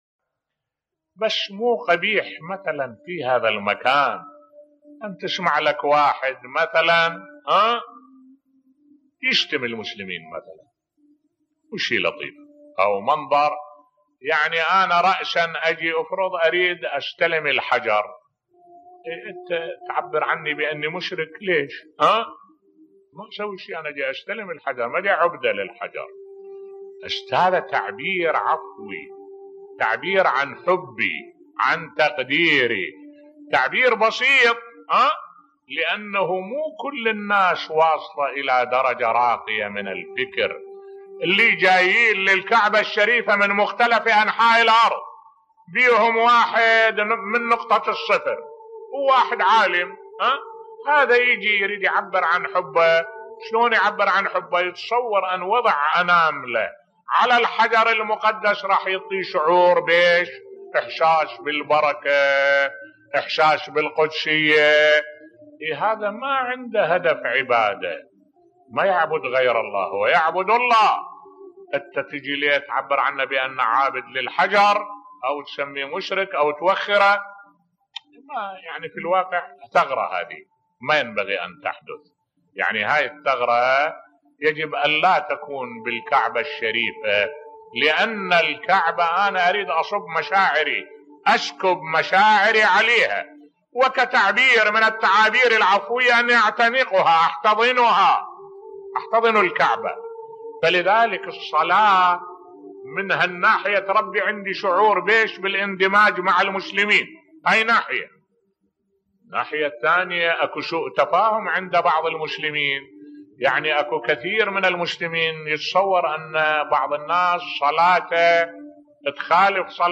ملف صوتی لا ترمي أخاك المسلم بالكفر بصوت الشيخ الدكتور أحمد الوائلي